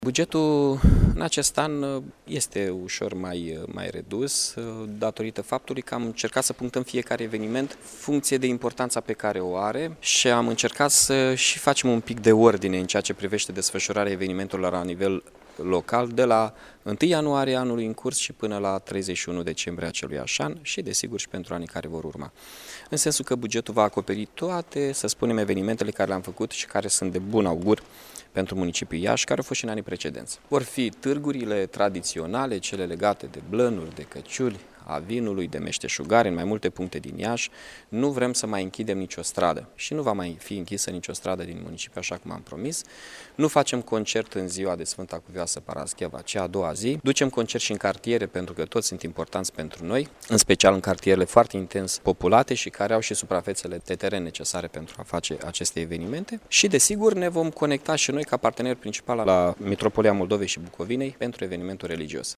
Precizarea a fost făcută de edilul Mihai Chirica care a precizat că focul de artificii nu va mai fi organizat cu ocazia Sărbătorilor Iaşului, ci va avea loc în noaptea de Revelion: